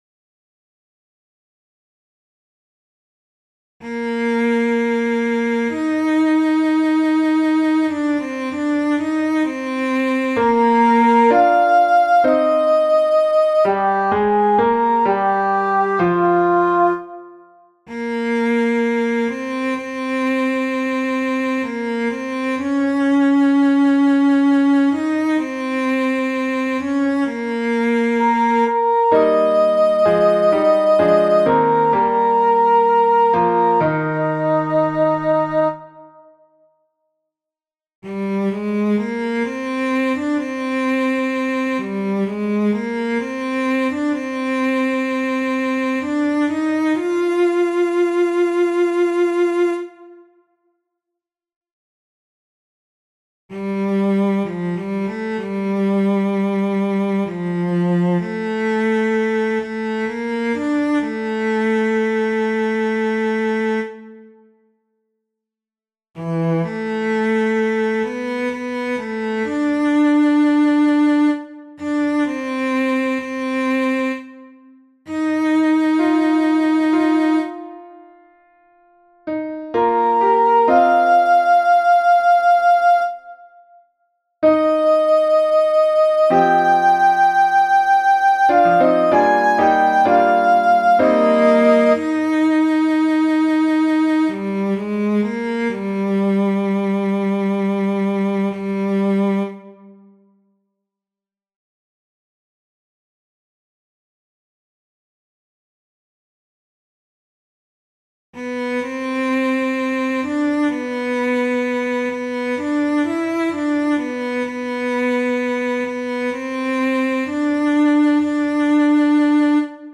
Northern Sinfonia of England
The baritone soloist sounds like a cello.
SOPRANO 1
five_mystical_songs_1.-soprano1.mp3